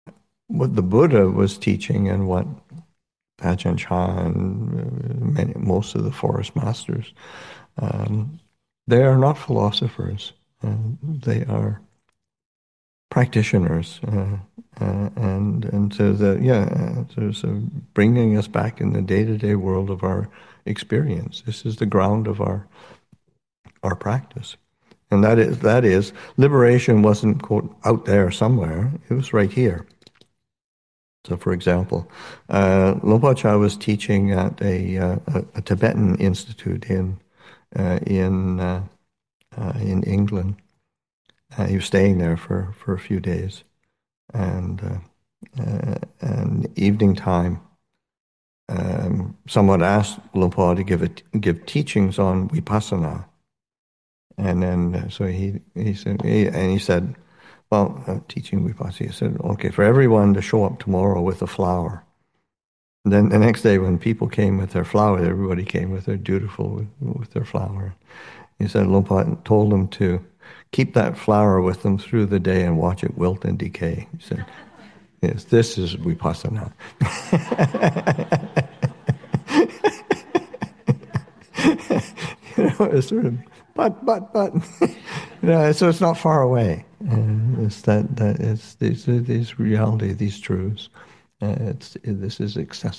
7. Story: When asked to teach about vipassanā